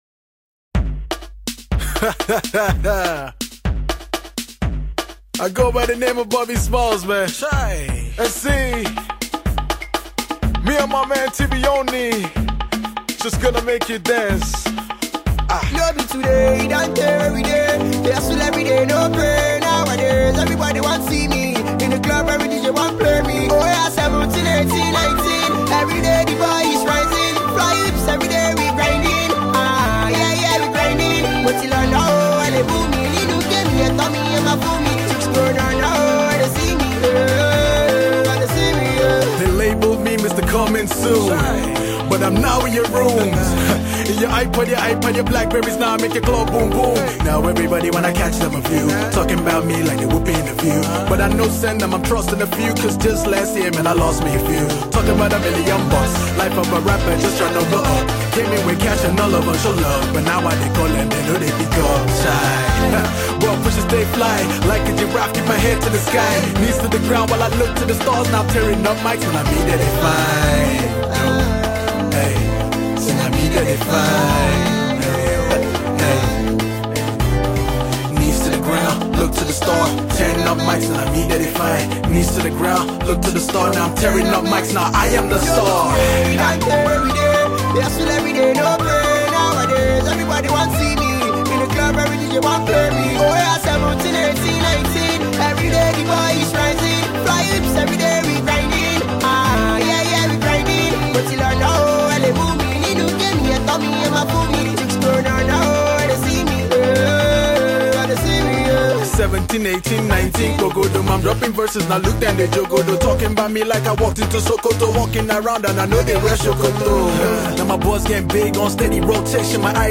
club-friendly